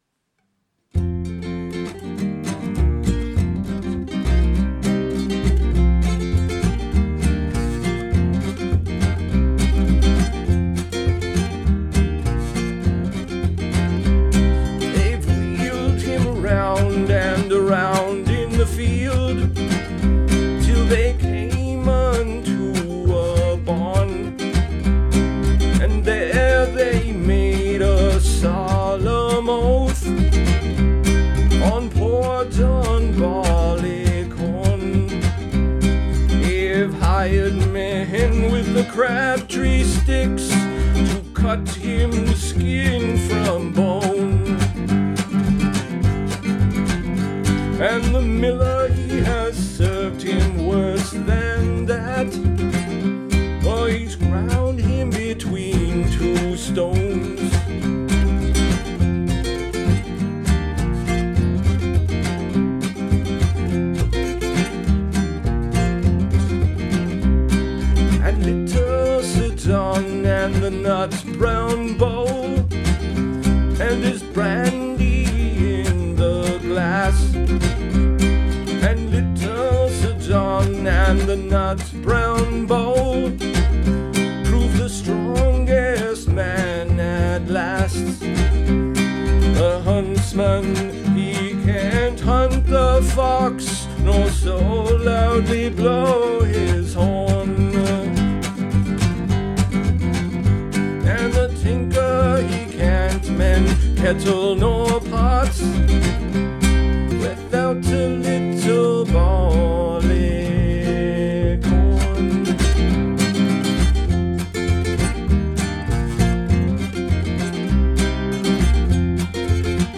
Genre: Folk Rock.